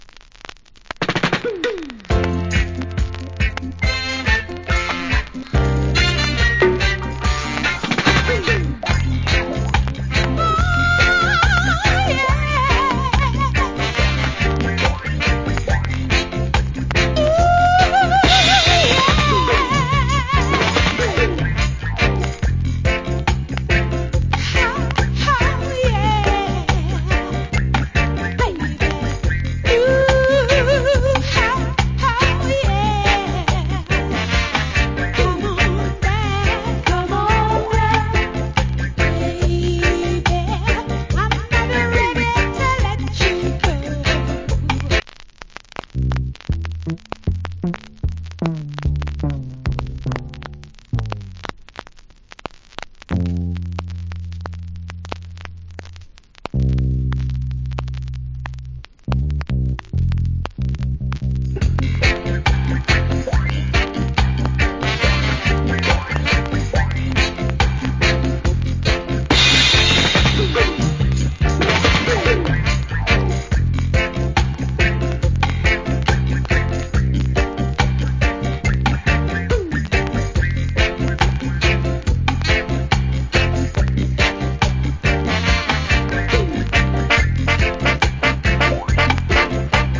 Nice Female Reggae Vocal.